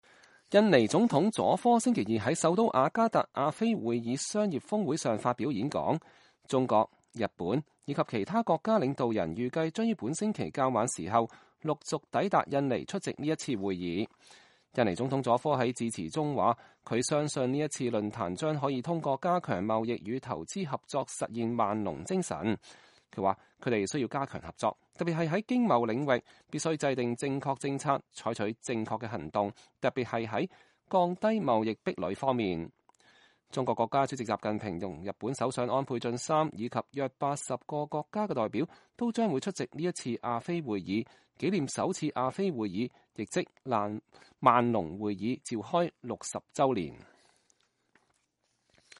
印尼總統在亞非會議商業峰會發表演講
印尼總統佐科星期二在首都雅加達亞非會議商業峰會上發表演講。